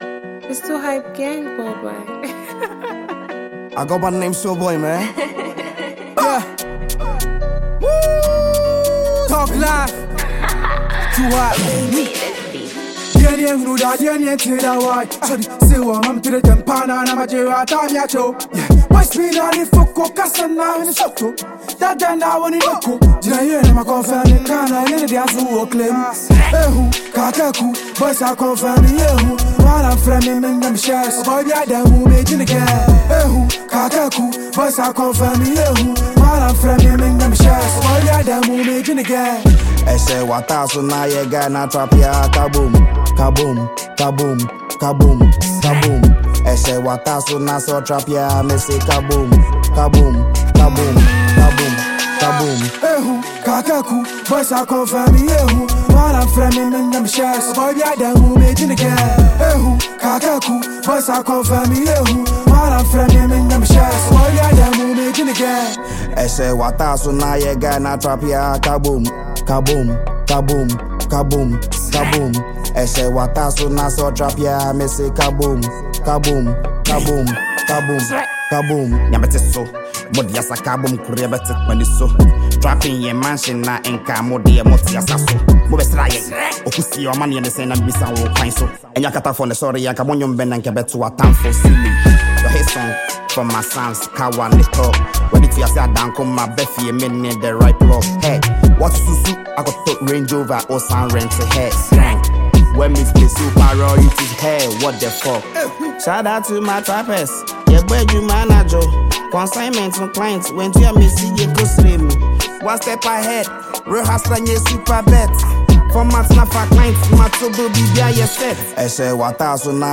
Ghana Music
signature energetic rap flow
infectious rhythm and powerful melodies
vocals are smooth and captivating
verses are filled with raw energy and clever wordplay